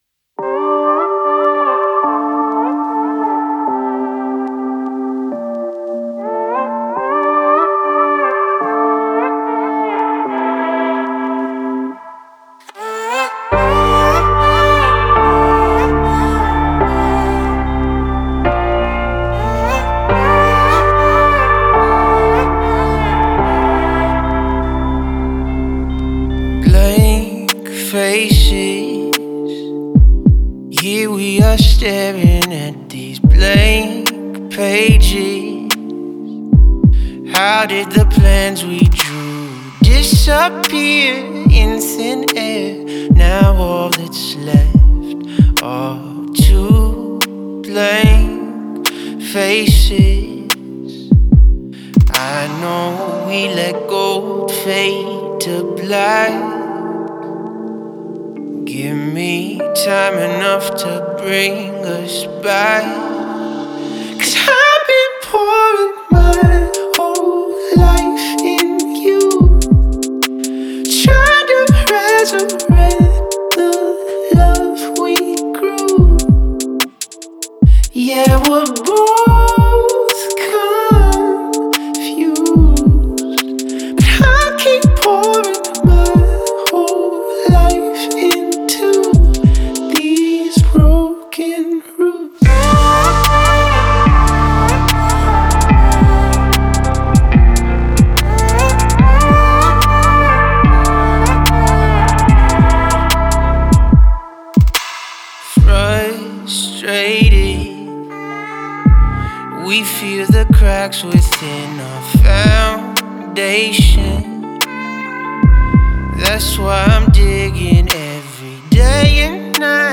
Жанр: R&B.